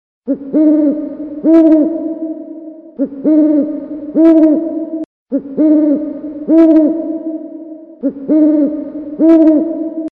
Kategorie Zwierzęta